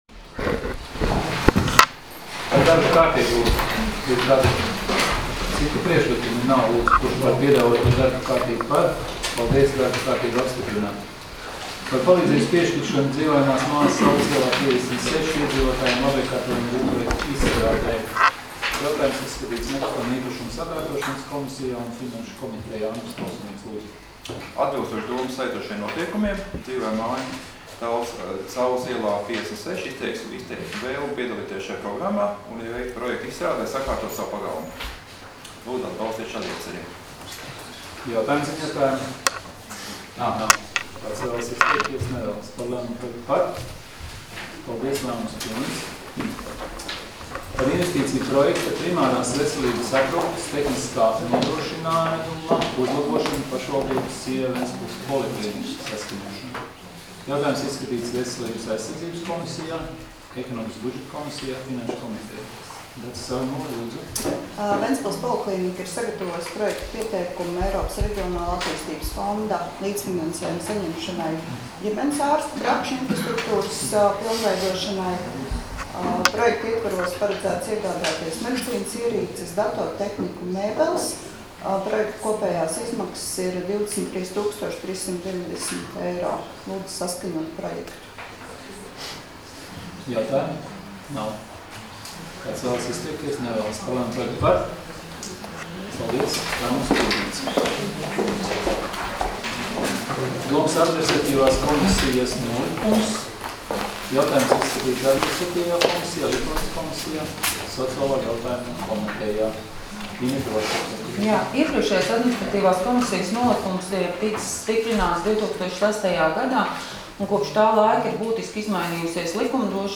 Domes sēdes 26.04.2019. audioieraksts